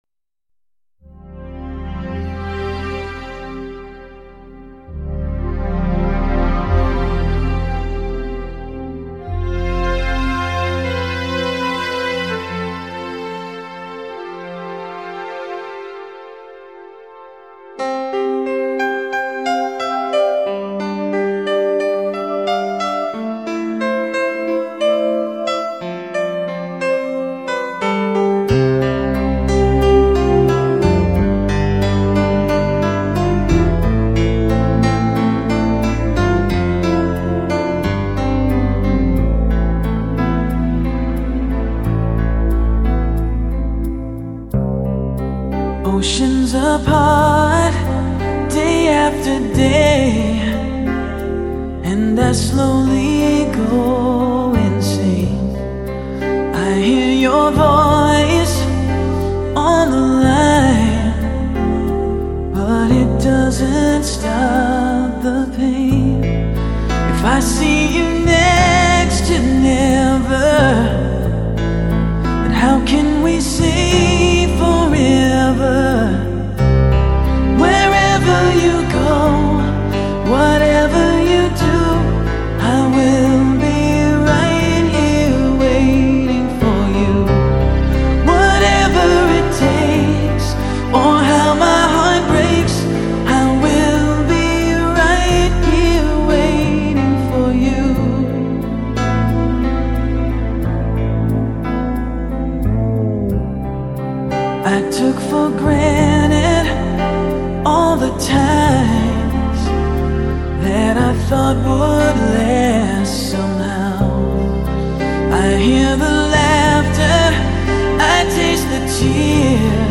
震天动地的强劲动感 举座皆惊的靓绝旋律